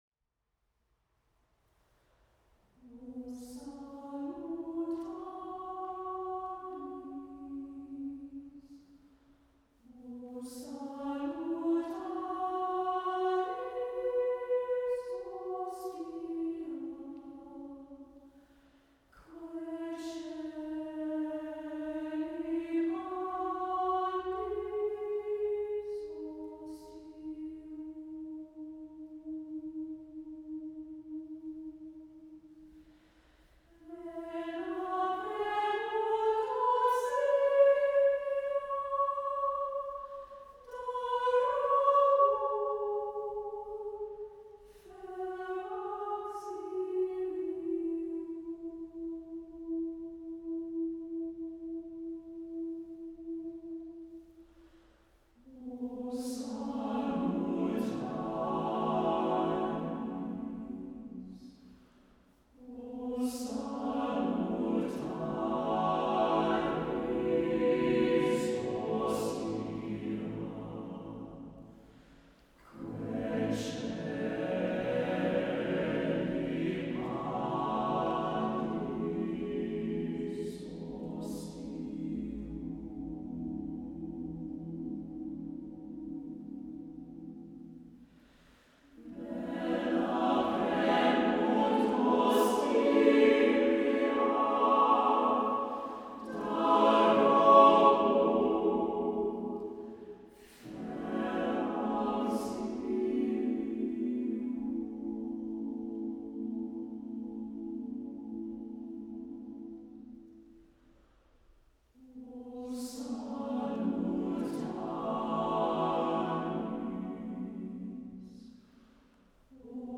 Voicing: SATB divisi a cappella